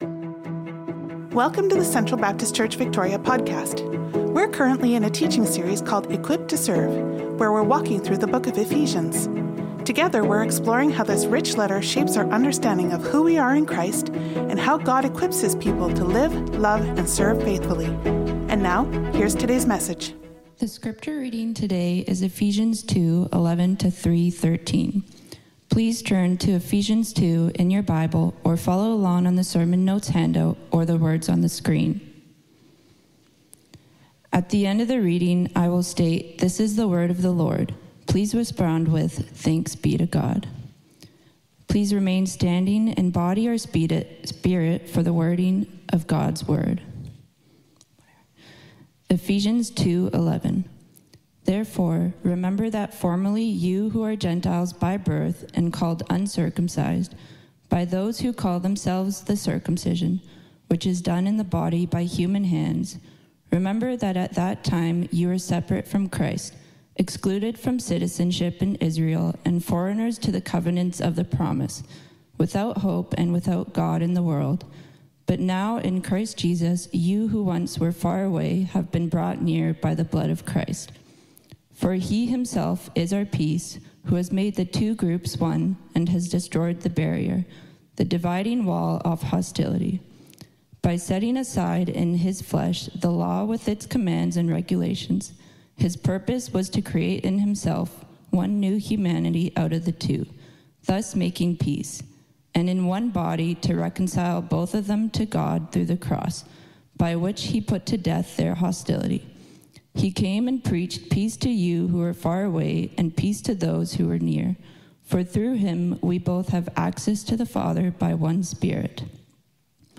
Sermons | Central Baptist Church